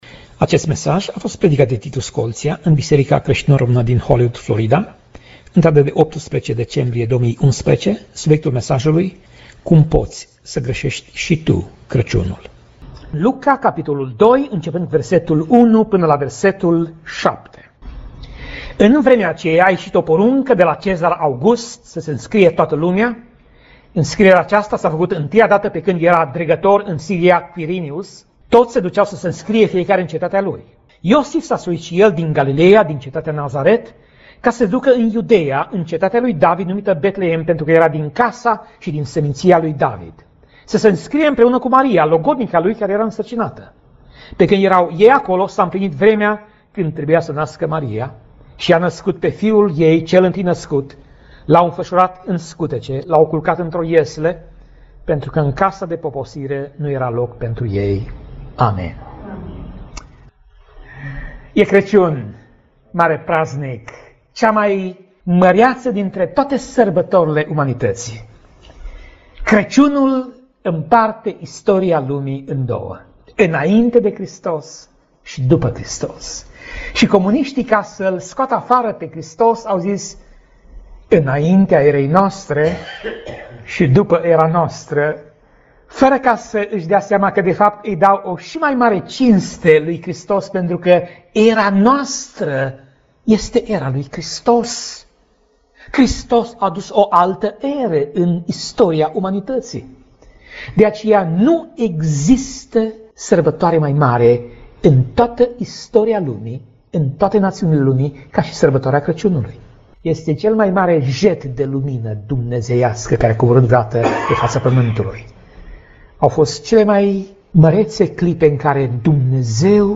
Pasaj Biblie: Luca 2:1 - Luca 2:7 Tip Mesaj: Predica